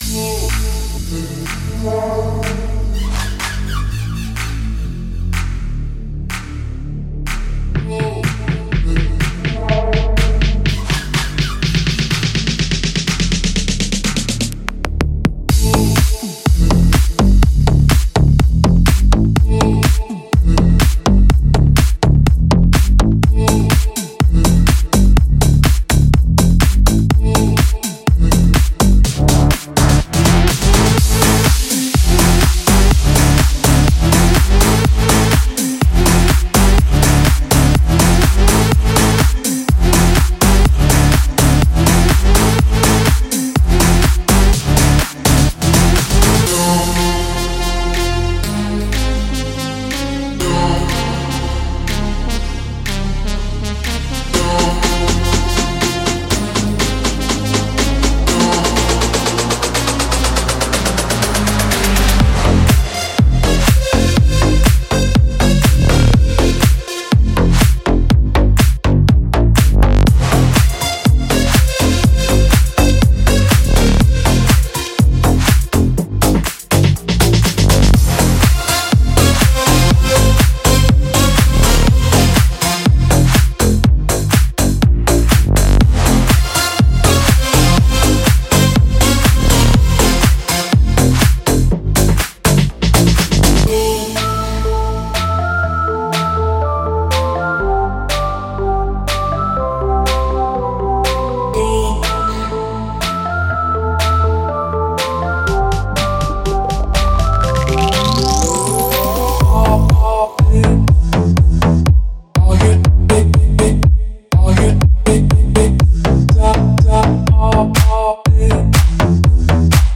激动人心的鼓音圈，深沉的提琴声，巧妙的FX和六个广泛的制作套件，可为您全面提供各种壮丽的声音。
• 10 Bass Hits
• 20 Synth Loops
• 40 Drum Loops